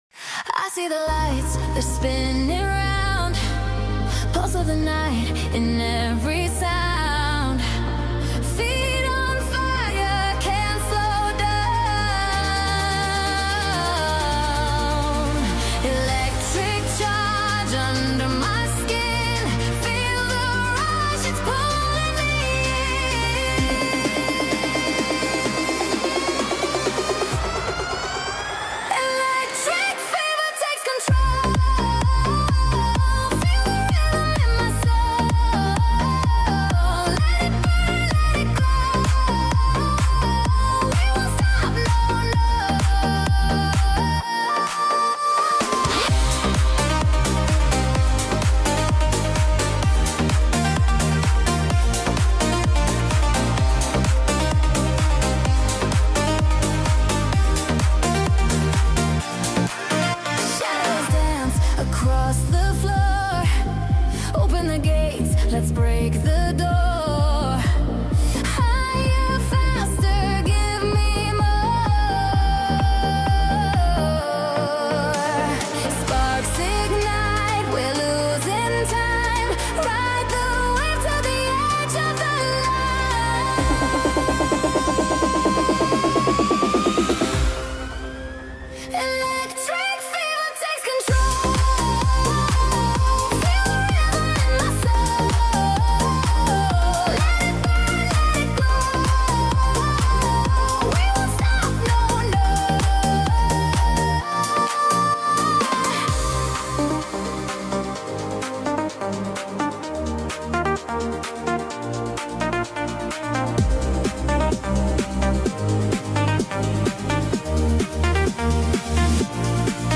New EDM Dance Music